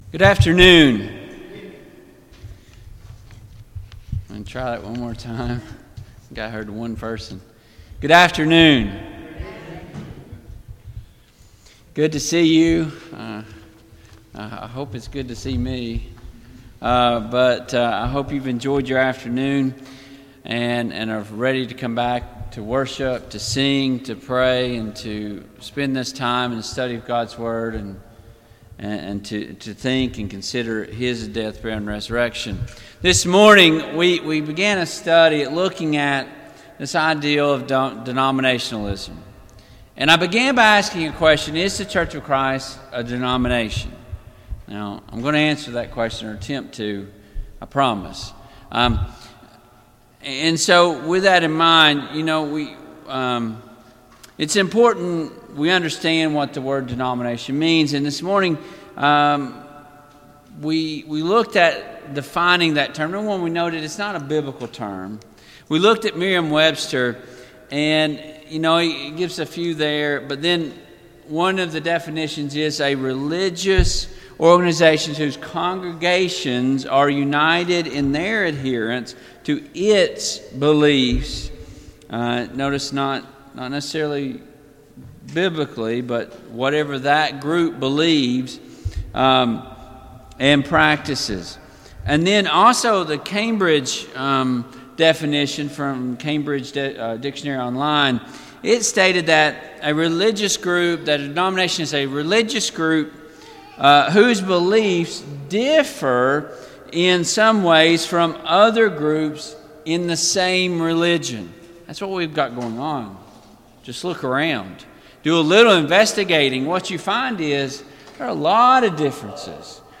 Service Type: PM Worship